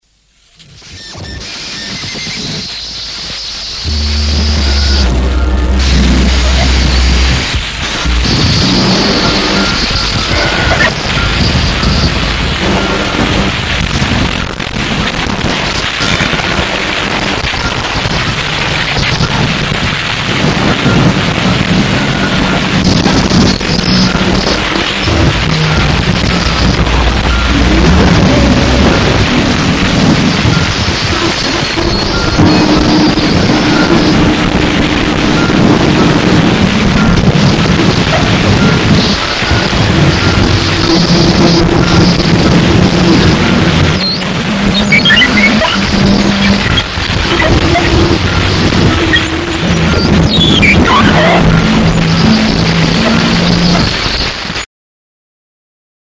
Noise